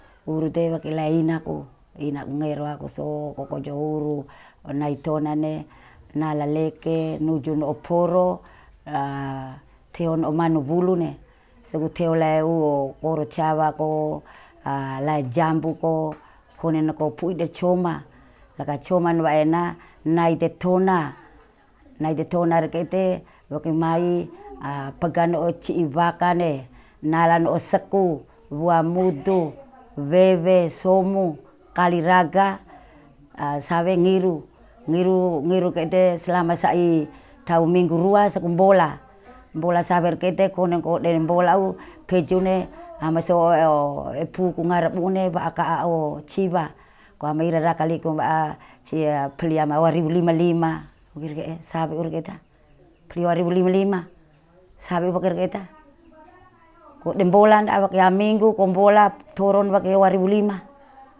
digital wav file recorded at 48 khz/24 bit
Recording made in kampong Teo, Teo domain.